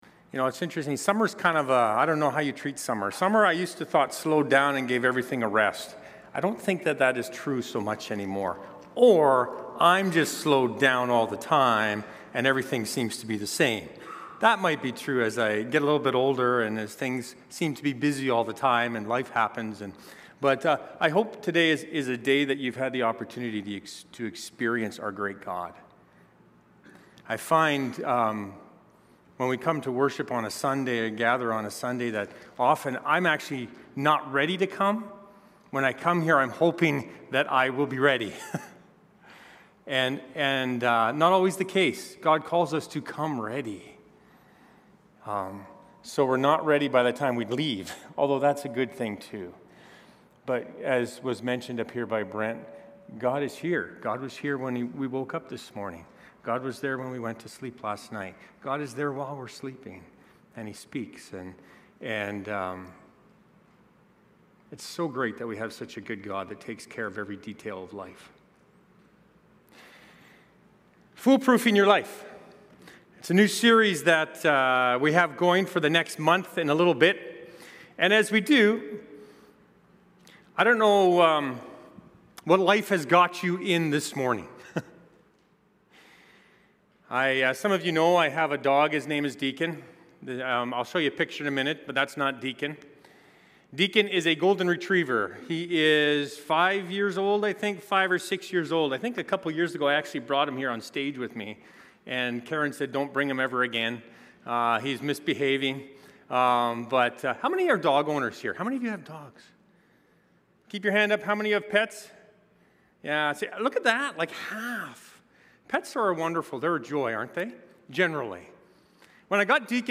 Immanuel Church Sermons | Immanuel Fellowship Baptist Church